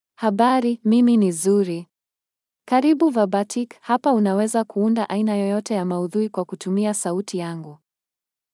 Zuri — Female Swahili AI voice
Zuri is a female AI voice for Swahili (Kenya).
Voice sample
Female
Zuri delivers clear pronunciation with authentic Kenya Swahili intonation, making your content sound professionally produced.